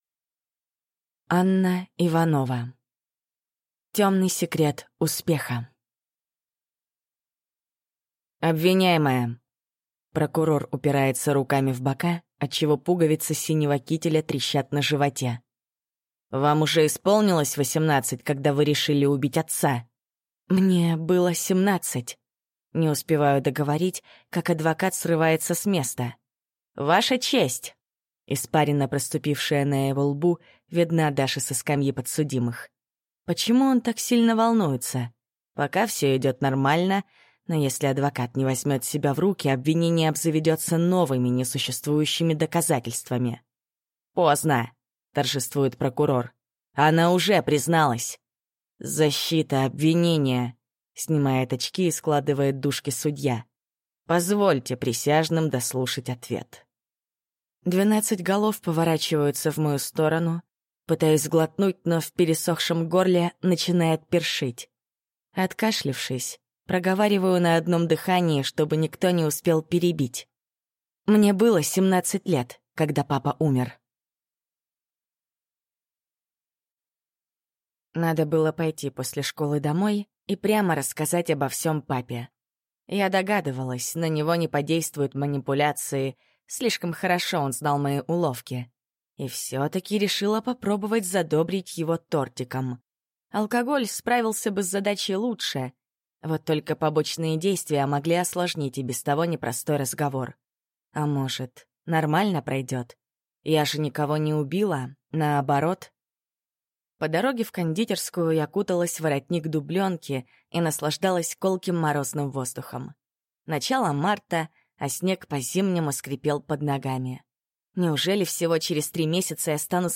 Аудиокнига Тёмный секрет успеха | Библиотека аудиокниг